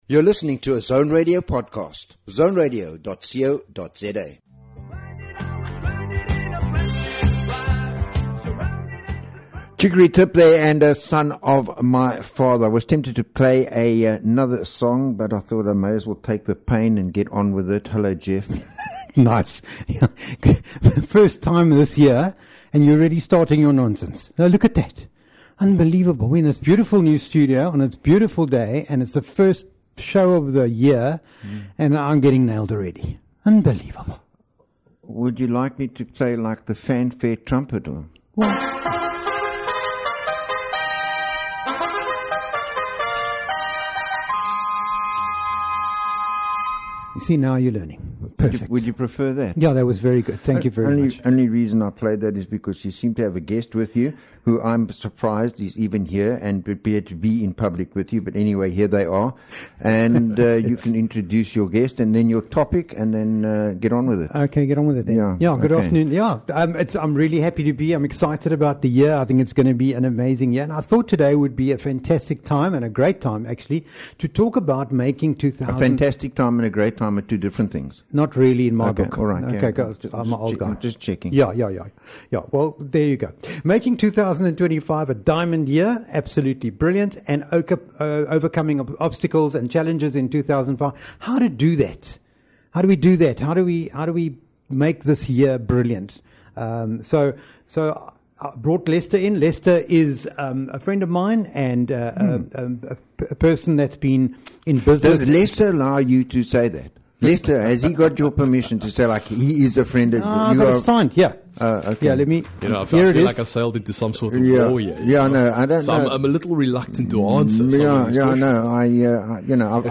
is a bi-weekly radio show exploring the rich tapestry of human experiences. Delve into the sweet and tangy moments of life, savoring conversations on relationships, wellbeing, and the flavors that make up our existence.